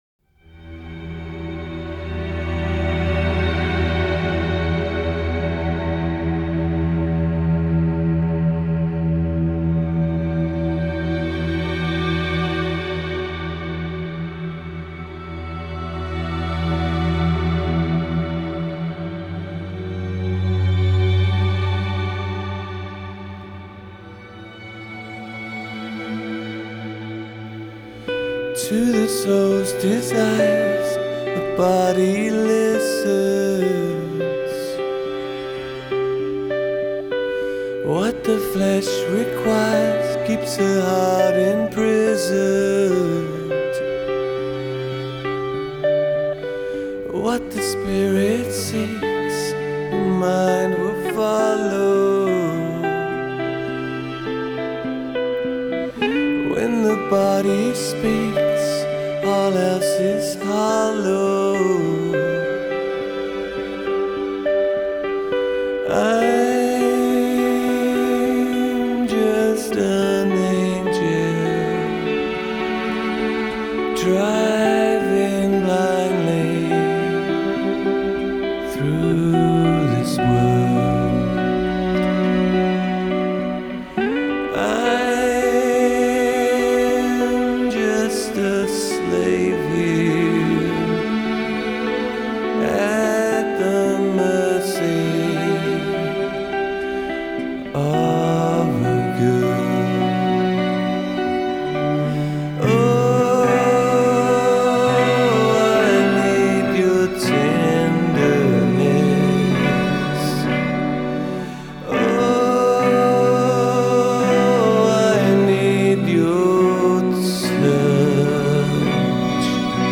Genre : Electronic